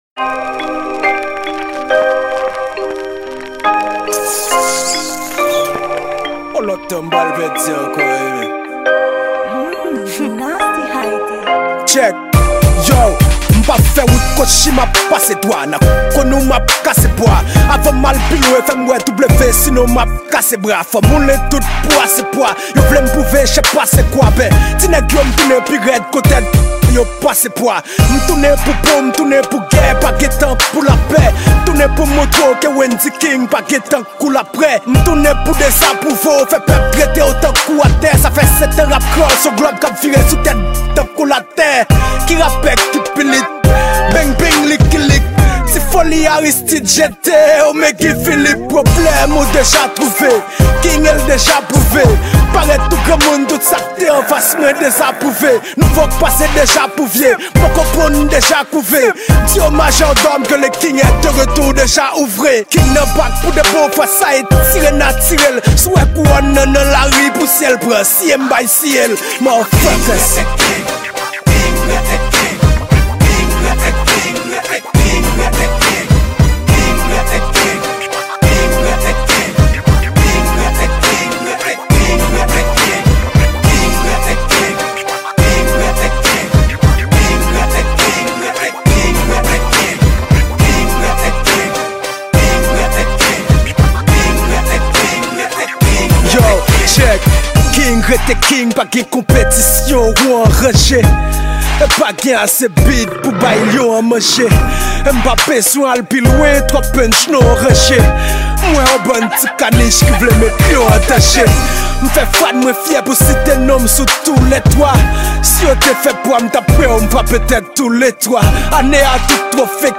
Genre : RAP